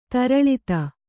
♪ taraḷita